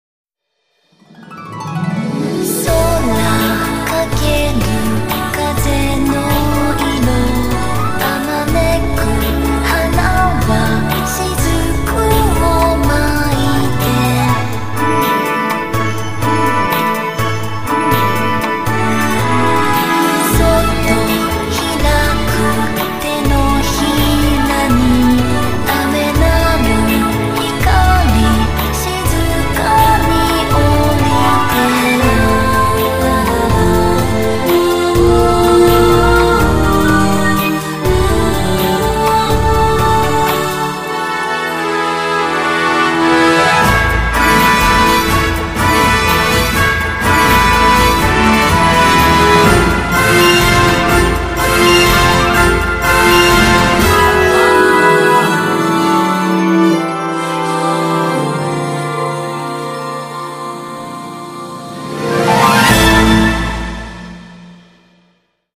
甜美温暖，却带着由自骨子里的邪气
天籁之音